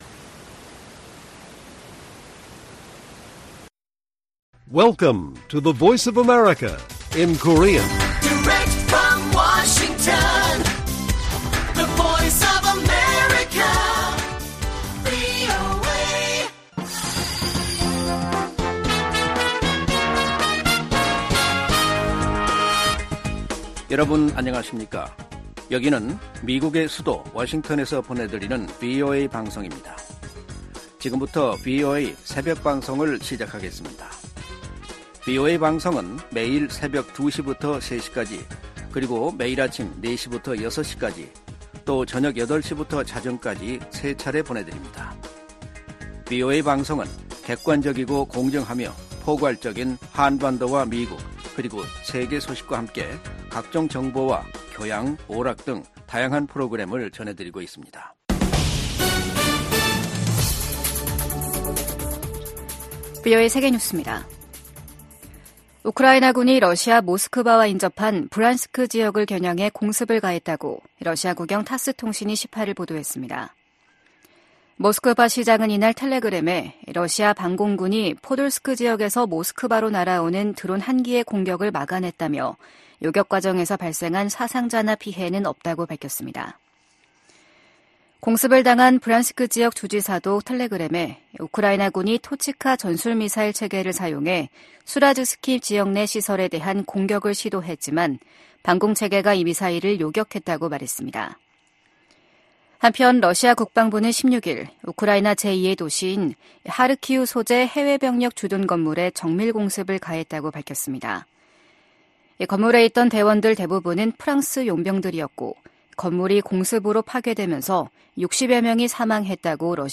VOA 한국어 '출발 뉴스 쇼', 2024년 1월 19일 방송입니다. 미국은 북한과 러시아의 무기 거래는 안보리 결의 위반이라고 비판하고, 북한 지도부에 외교에 복귀하라고 촉구했습니다. 미한일 북 핵 수석대표들이 북한에 긴장을 고조시키는 언행과 도발, 무모한 핵과 미사일 개발을 중단할 것을 요구했습니다. 국제 기독교선교단체 '오픈도어스'가 2024 세계 기독교 감시 보고서에서 북한을 기독교 박해가 가장 극심한 나라로 지목했습니다.